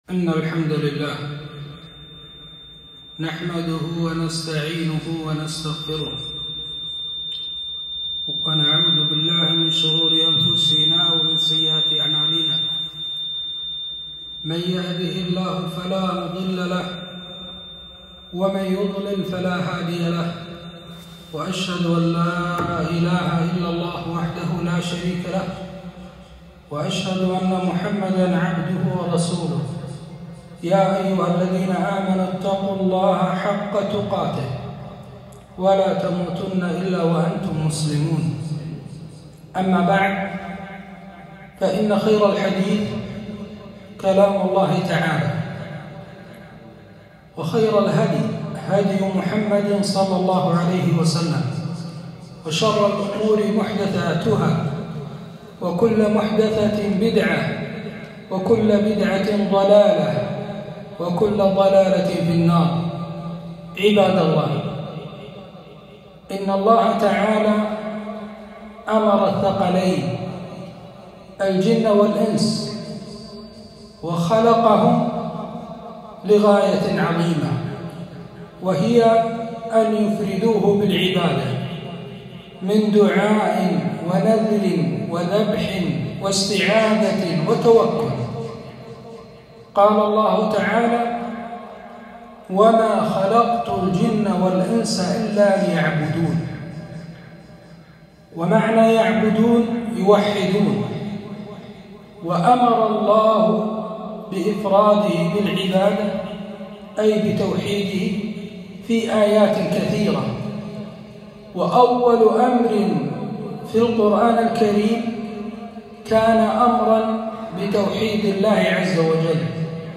خطبة - التوحيد حق الله على العبيد